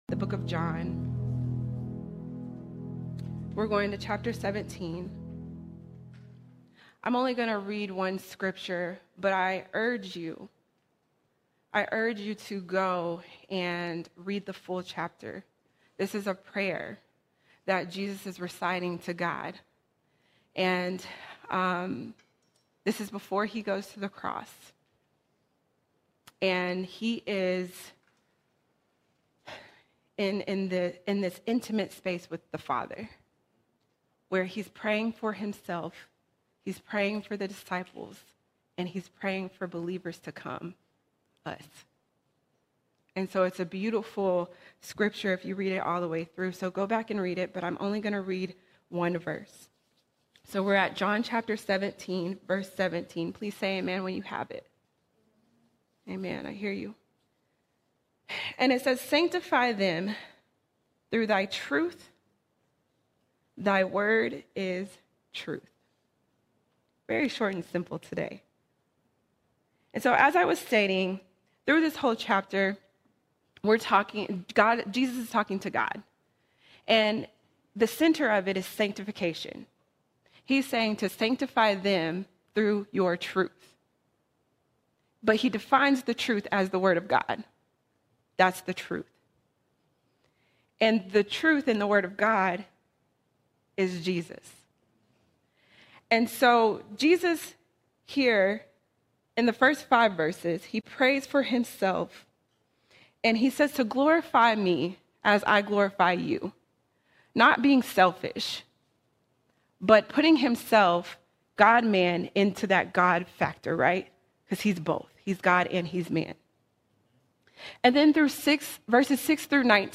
29 December 2025 Series: Sunday Sermons All Sermons Daily Bread, Daily Sanctification Daily Bread, Daily Sanctification The Word of God is our daily bread.